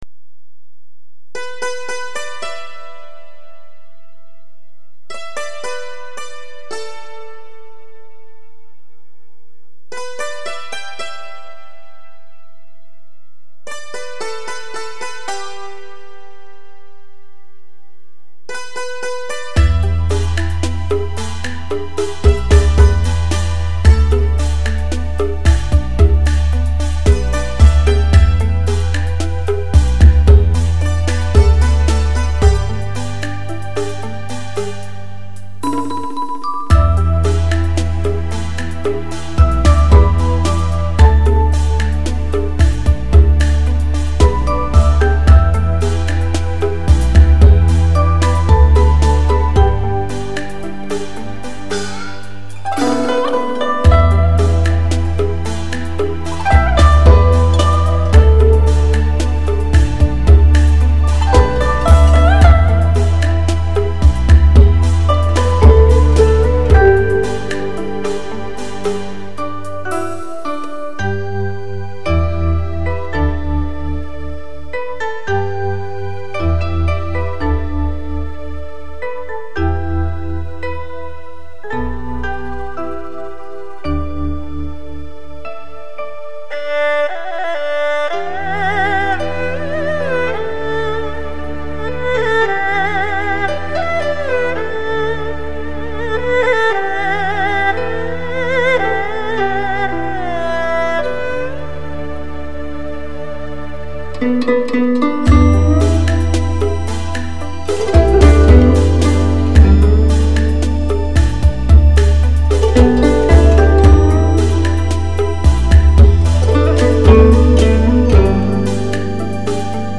演出：笙、排簫、箏......等
隨性而放肆用極具節奏感的現代手法，玩起了各種民族音樂
以輕舞曲風的上海民謠